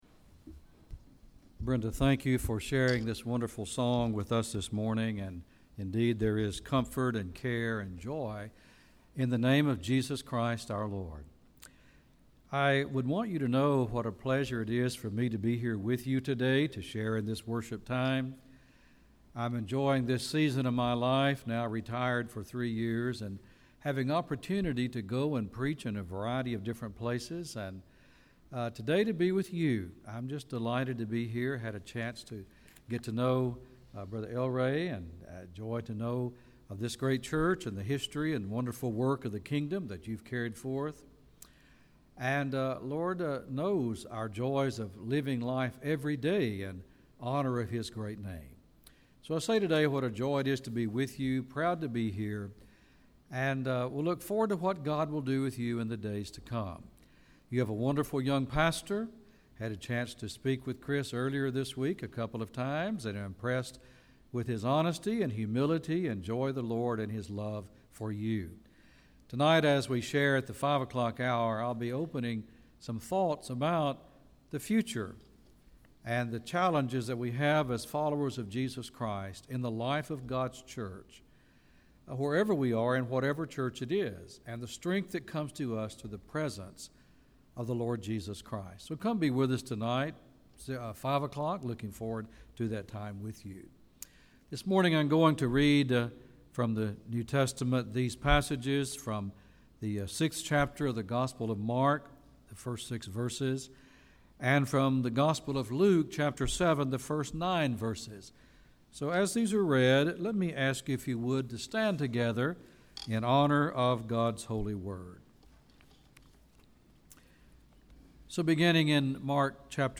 Sunday Sermon June 9, 2019